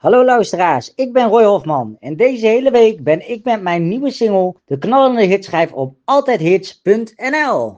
voor alle liefhebbers van de Nederlandse Volkse Muziek